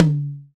TOM TOM131.wav